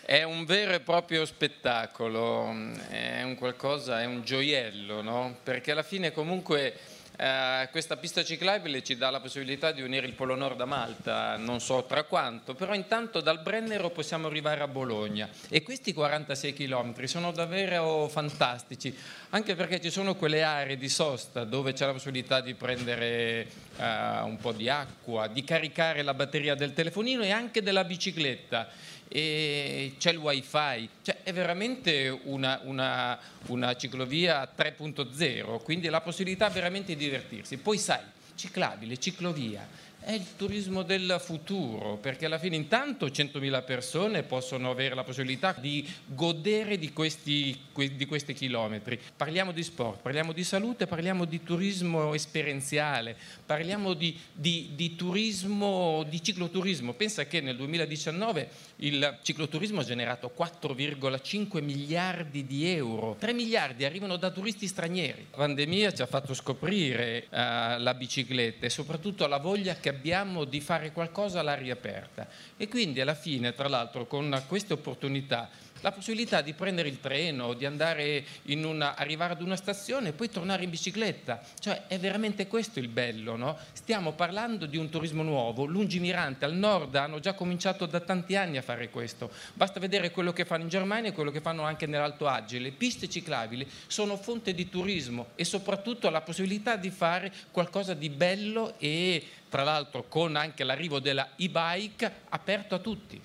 Alla cerimonia di inaugurazione, svoltasi senza pubblico e nel rispetto delle restrizioni anti-Covid,  ha partecipato anche il CT della nazionale di ciclismo italiana Davide Cassani che ha avuto l’onore di percorrere in anteprima il tracciato: